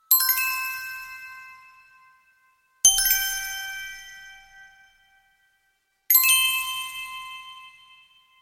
На этой странице собраны разнообразные звуки сосулек — от нежного звона капель до резкого обрыва ледяных глыб.